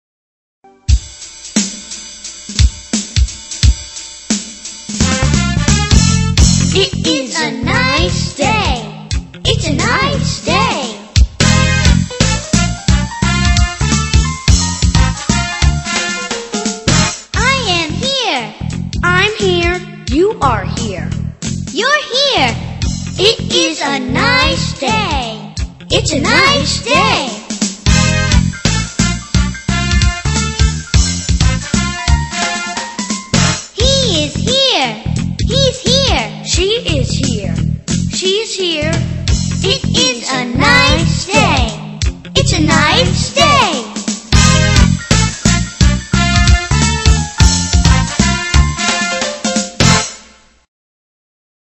在线英语听力室英语儿歌274首 第99期:It is a nice day的听力文件下载,收录了274首发音地道纯正，音乐节奏活泼动人的英文儿歌，从小培养对英语的爱好，为以后萌娃学习更多的英语知识，打下坚实的基础。